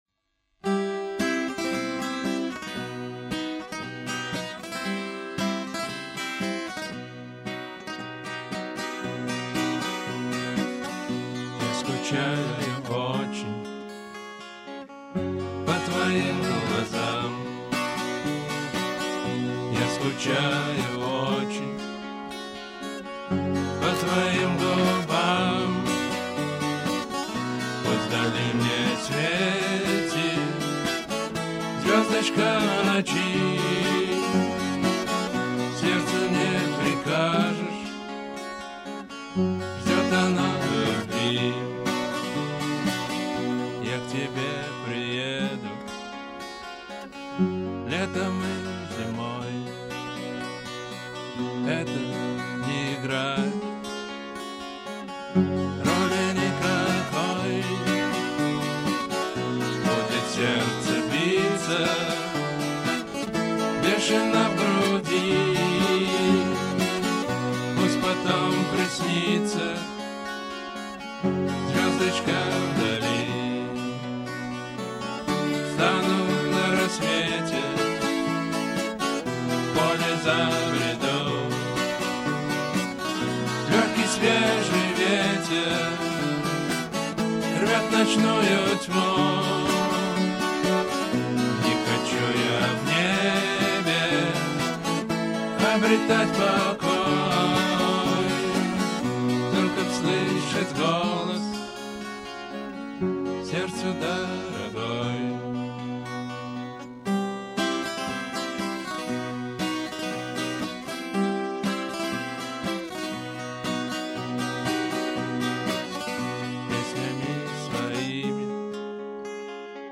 Поп Рок Авторские песни